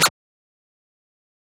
perc 14.wav